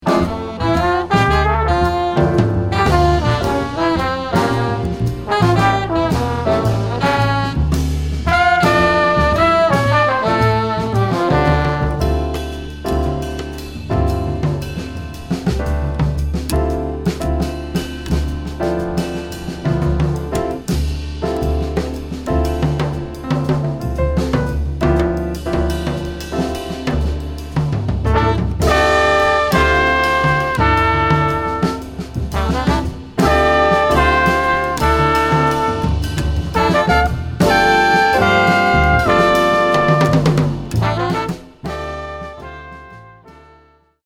Voicing: 3Horns/Rhy